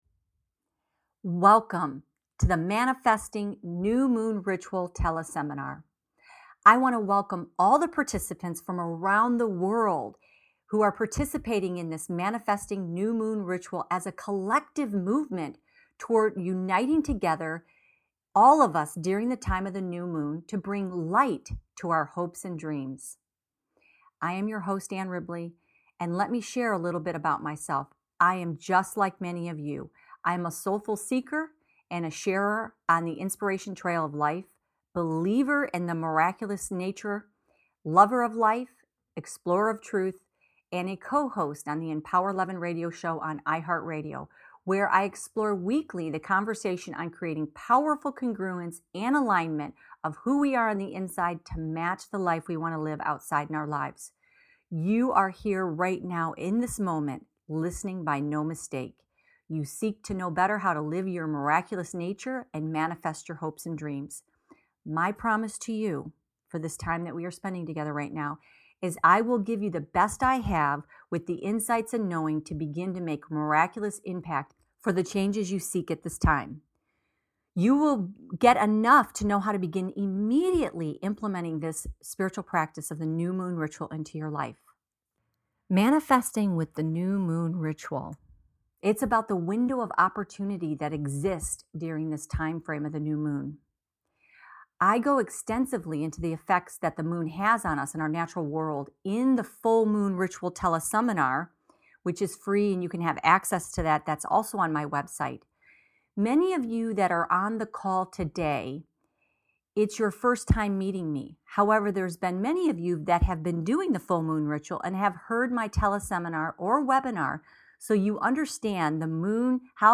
Manifesting New Moon Ritual Teleseminar
New+Moon+Teleseminar+Final.mp3